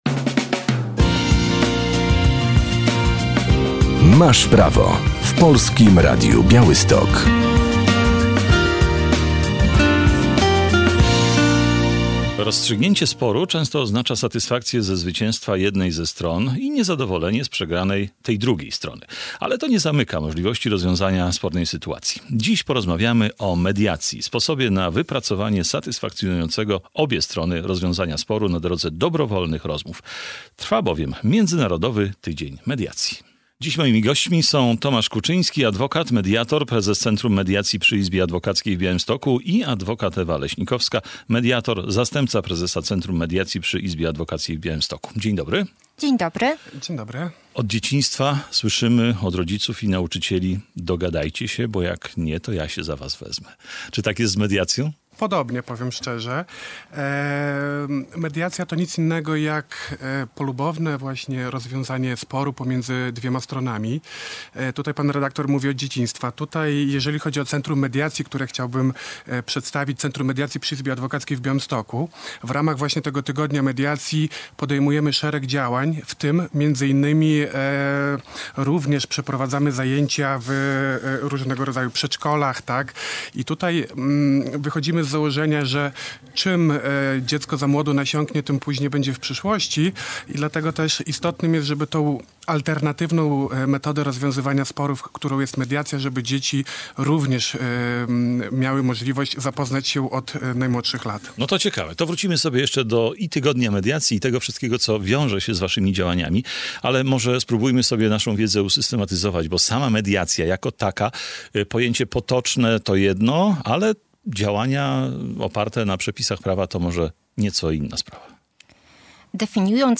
Trwa Międzynarodowy Tydzień Mediacji. Dlatego w audycji rozmawiamy o tym sposobie na wypracowanie